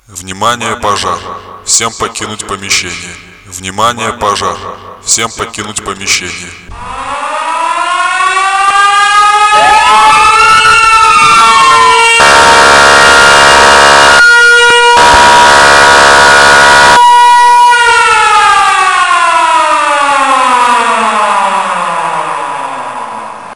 Звуки пожарной сирены, тревоги
Внимание, пожарная тревога, всем срочно покинуть помещение